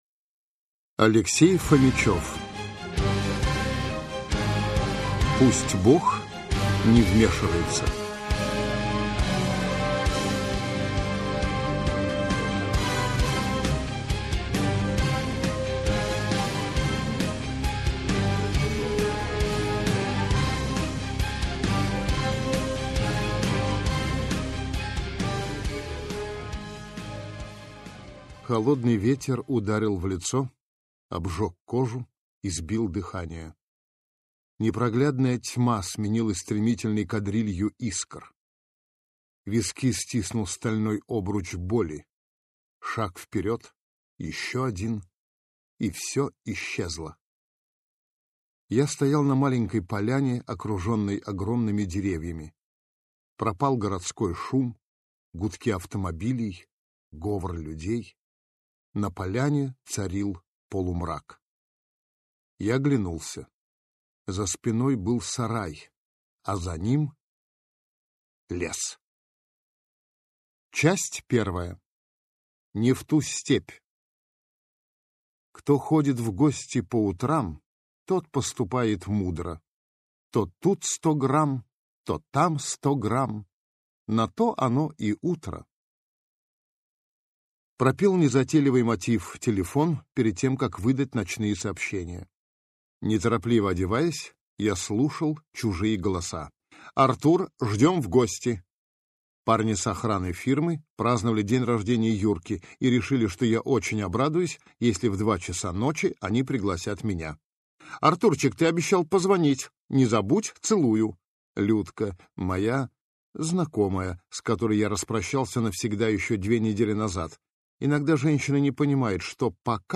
Аудиокнига Пусть Бог не вмешивается | Библиотека аудиокниг